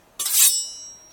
sword.10.ogg